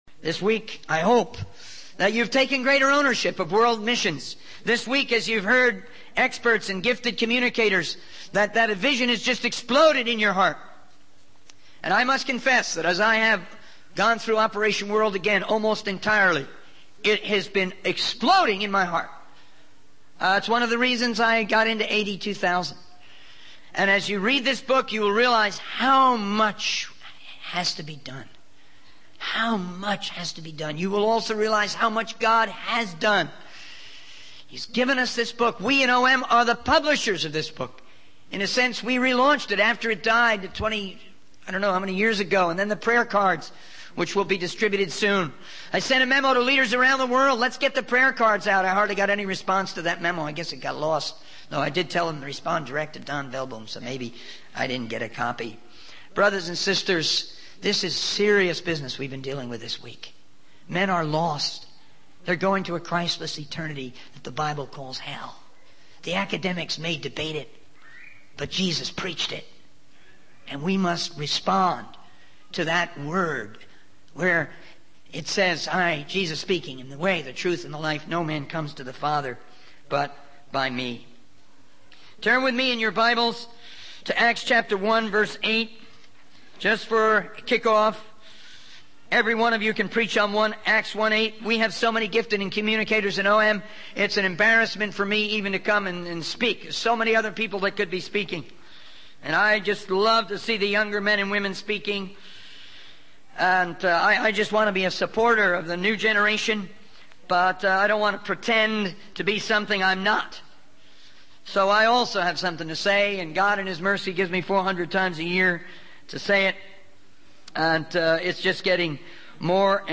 In this sermon, the speaker emphasizes the importance of having a vision and increasing our spiritual vision.